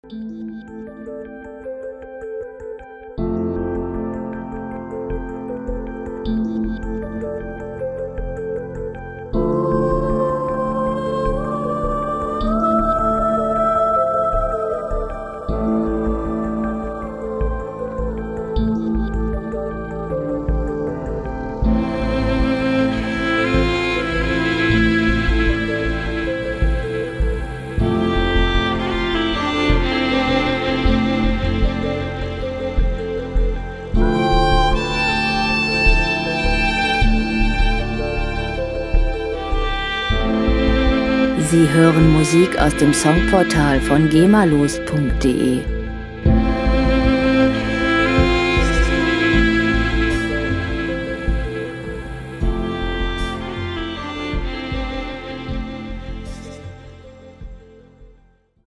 Filmmusik
Musikstil: Soundtrack
Tempo: 96 bpm
Tonart: A-Moll
Charakter: magisch, übersinnlich